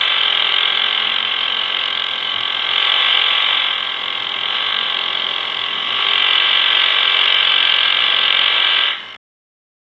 Звук вибратора